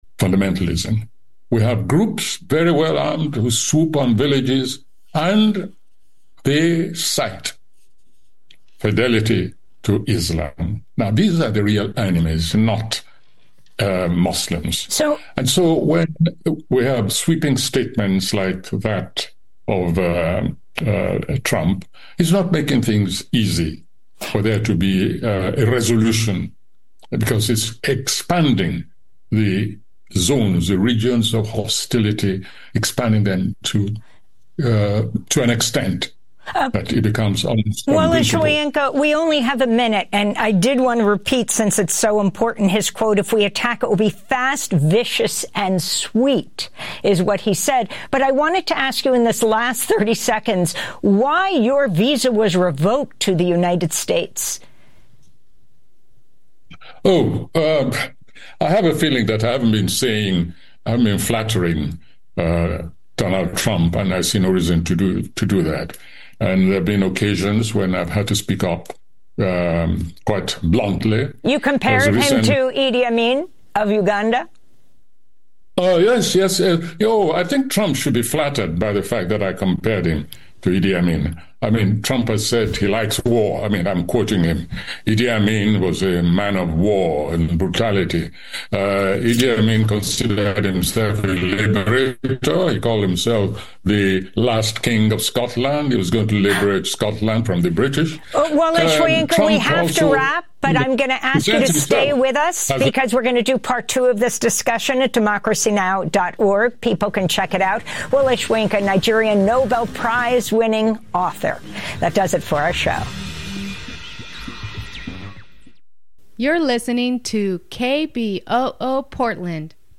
Evening News on 11/03/25